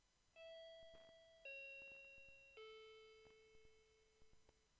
klingel_aufnahme_microphne_cut.wav